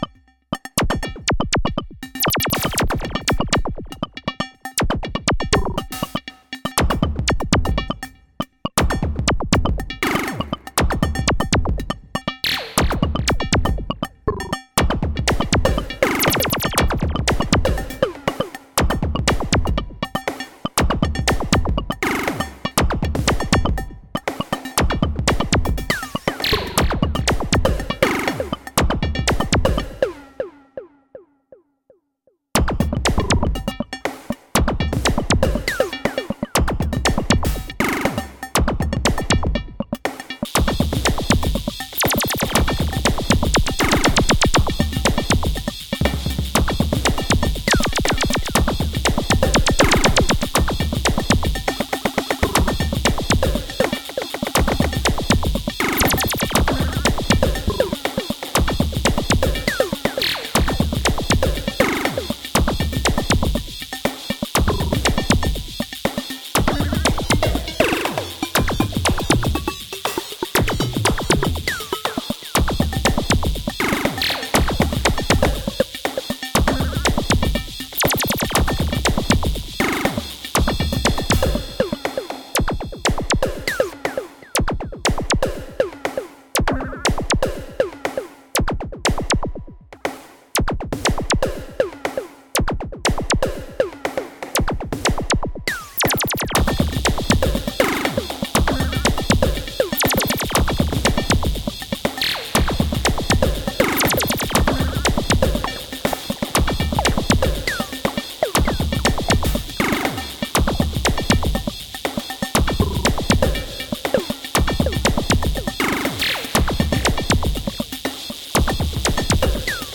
More polymetric rhythms from the Elektron Model:Cycles.
It's only a 1 bar pattern but through differing track lengths, probability-based sequencing and conditional triggers, I got a much more lively sounding song.